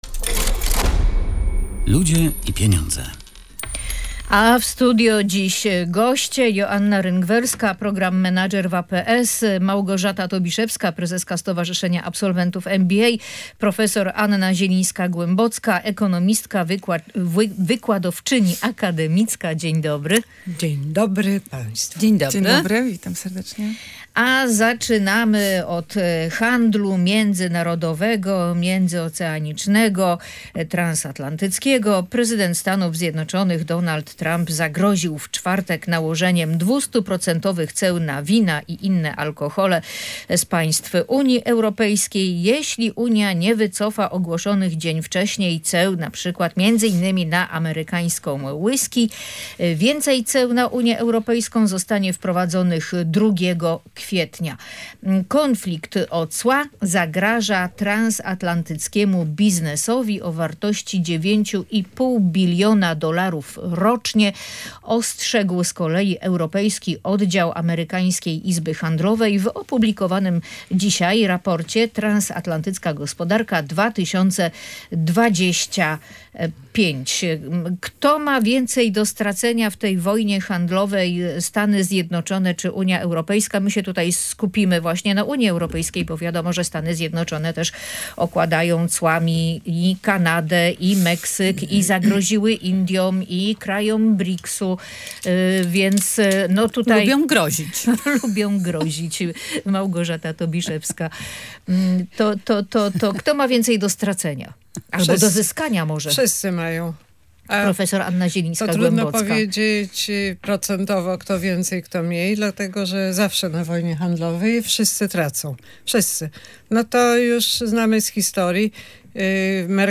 Gośćmi audycji „Ludzie i Pieniądze” były